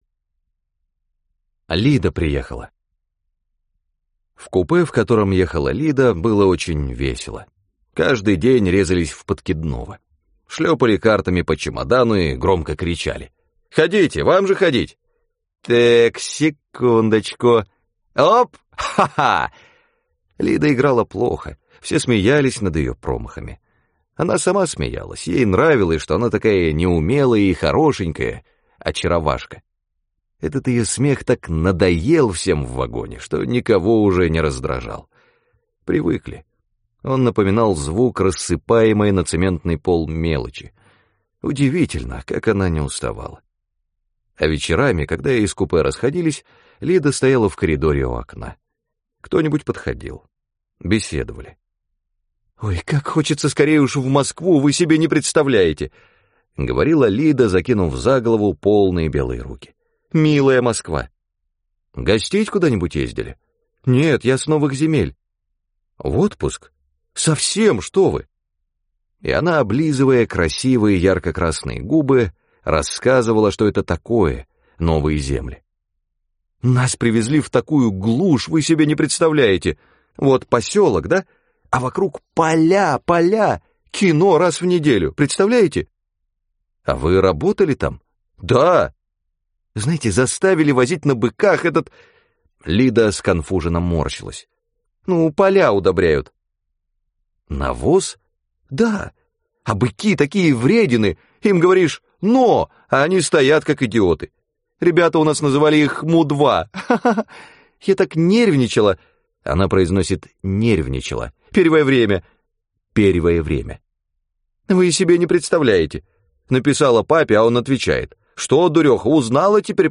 На данной странице вы можете слушать онлайн бесплатно и скачать аудиокнигу "Лида приехала" писателя Василий Шукшин. Включайте аудиосказку и прослушивайте её на сайте в хорошем качестве.